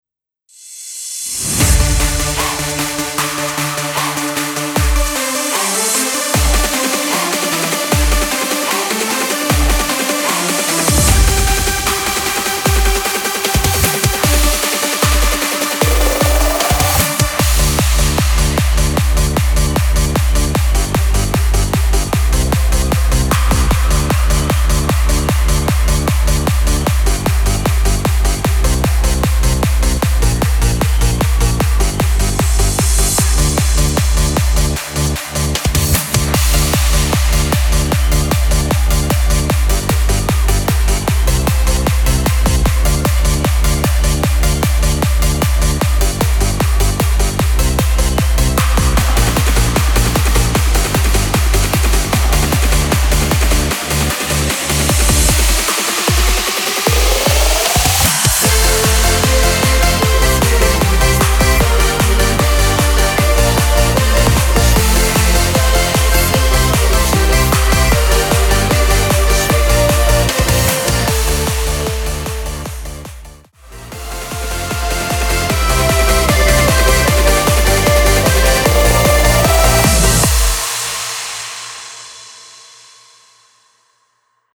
Karaoke Version: ja
Tonart: F Dur Karaoke Version mit Chor